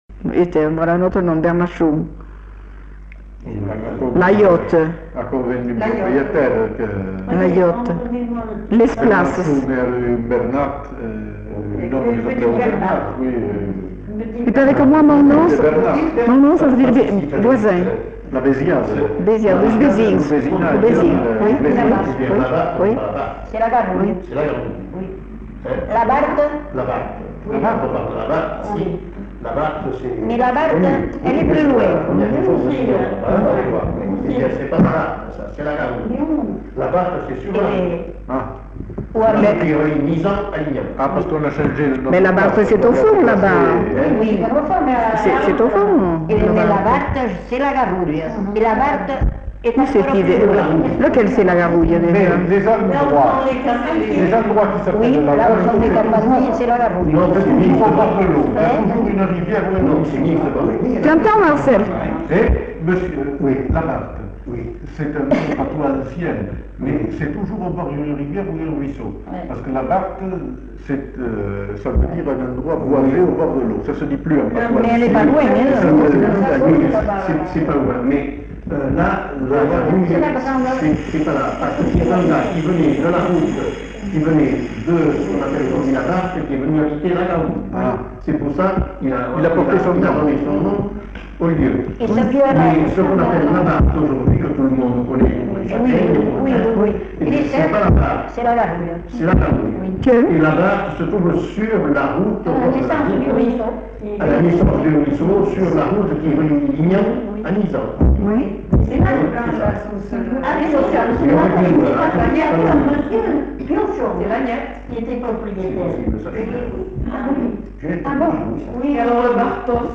Discussion sur Uzeste
Aire culturelle : Bazadais
Lieu : Uzeste
Genre : parole